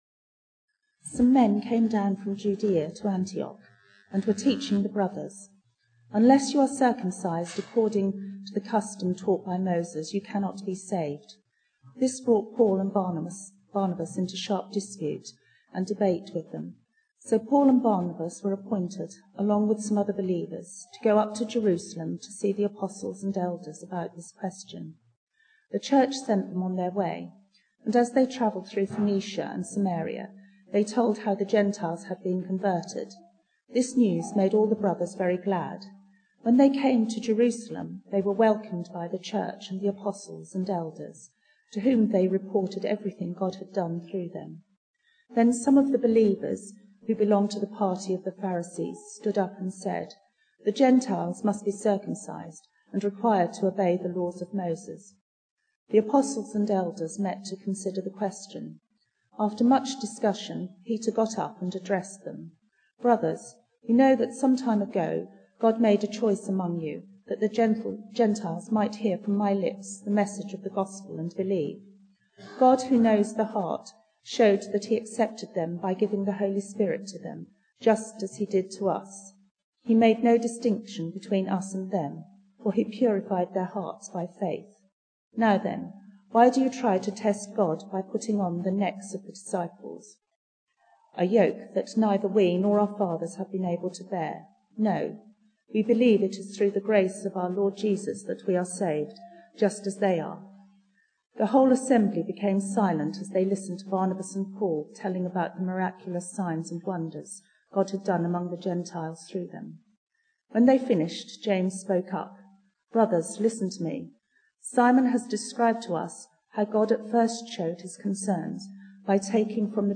Acts 15 Service Type: Sunday Evening Bible Text